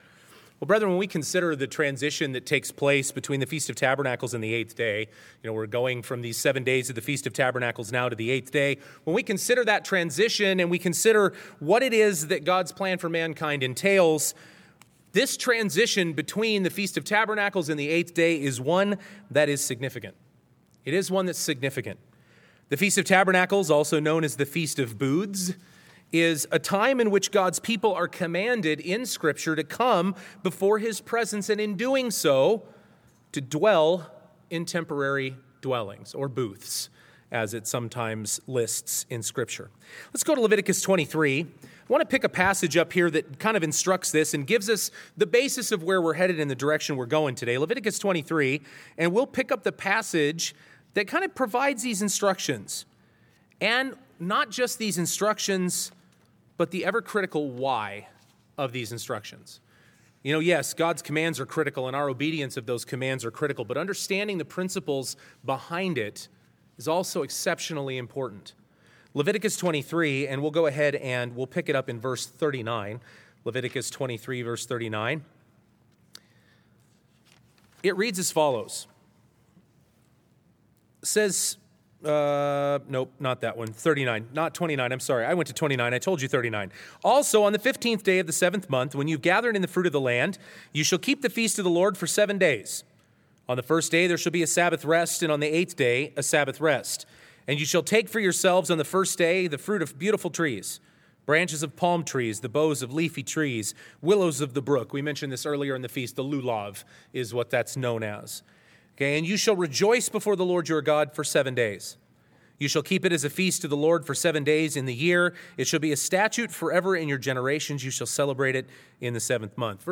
This sermon was given at the Bend-Redmond, Oregon 2021 Feast site.